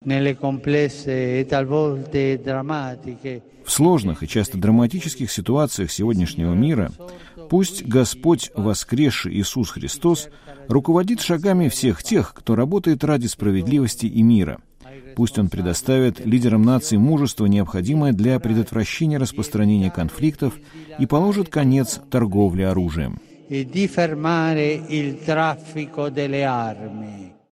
Папа римский Франциск выступил с традиционным пасхальным обращением, известном как Urbi et Orbi ("Граду и миру"), с балкона базилики Святого Петра в Ватикане